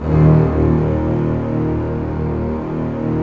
Index of /90_sSampleCDs/Roland - Brass, Strings, Hits and Combos/ORC_Orc.Unison p/ORC_Orc.Unison p